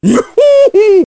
One of Donkey Kong's voice clips in Mario Kart DS